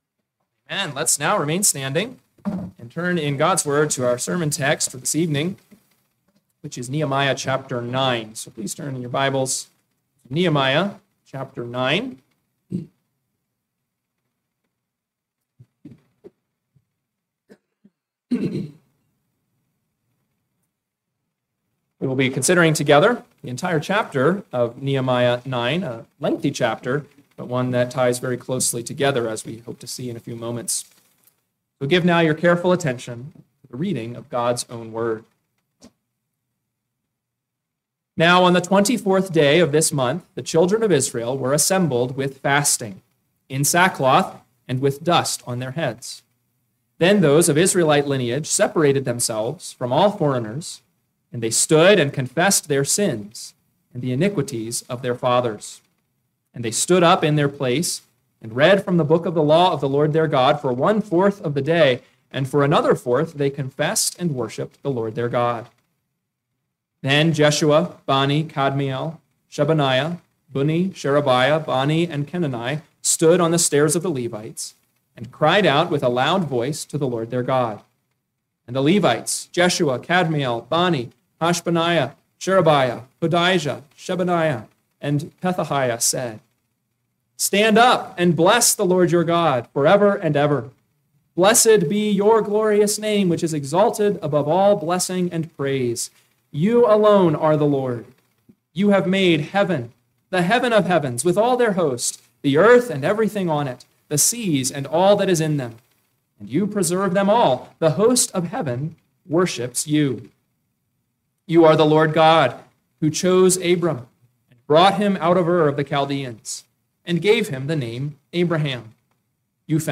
PM Sermon – 10/19/2025 – Nehemiah 9 – Northwoods Sermons